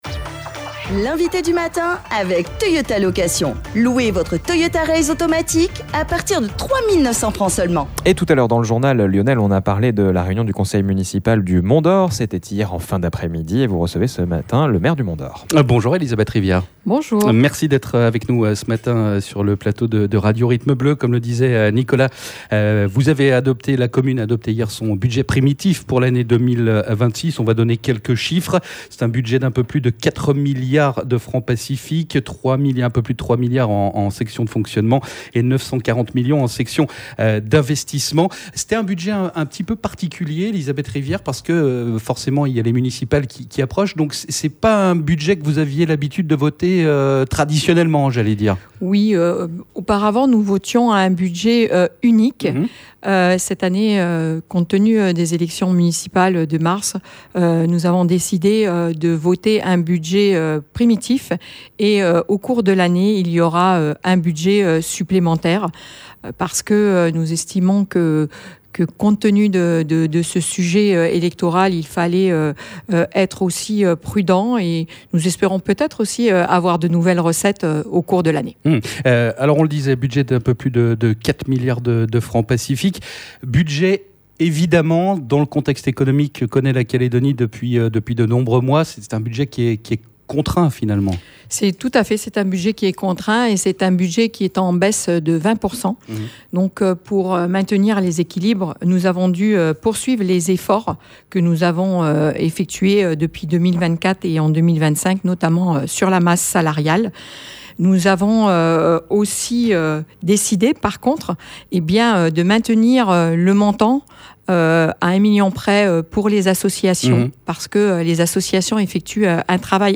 Dans le contexte économique particulier que la nouvelle Calédonie traverse depuis plus d’un an et demi, les élus au conseil municipal ont adopté le budget primitif 2026 de la commune. Un budget d'un peu plus de quatre milliards CFP. On en parle avec Elizabeth Rivière le Maire du Mont-Dore.